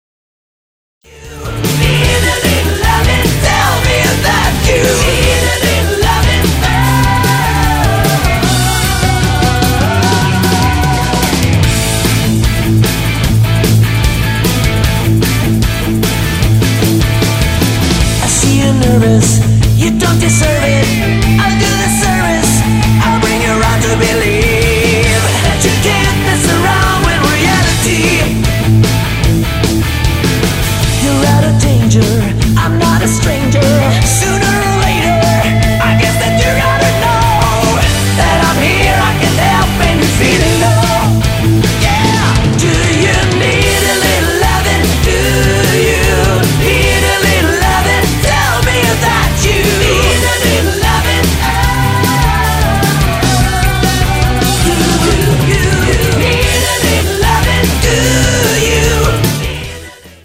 Lead vocals, backing vocals, keyboards & guitar
Lead and rhythm guitars
Drums, percussion and backing vocals
Electric bass guitar, lead and backing vocals
Keyboards, backing vocals and “The Big Knob” as usual